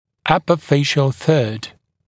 [‘ʌpə ‘feɪʃl θɜːd][‘апэ ‘фэйшл сё:д]верхняя треть лица